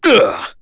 Chase-Vox_Damage_01.wav